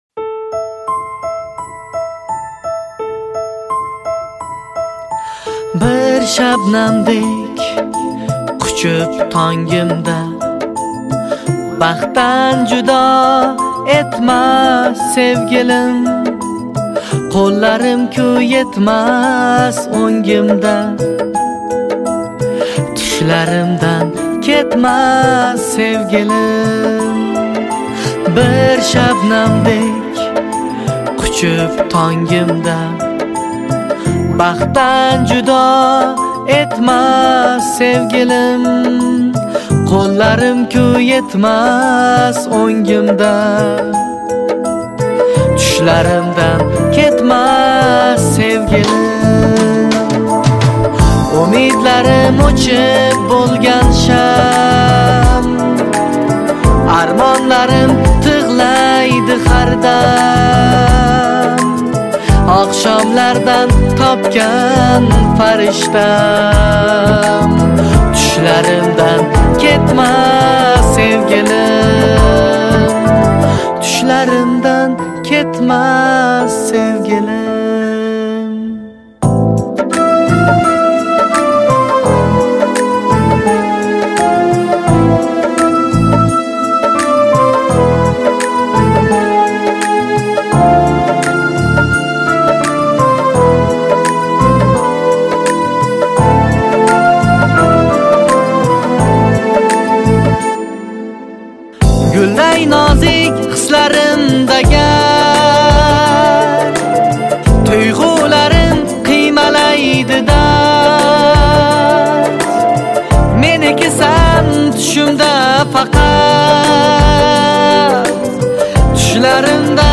Узбекский песни